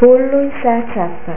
(pronuncia)   pollo
pullu_in_s+a_ciappa.au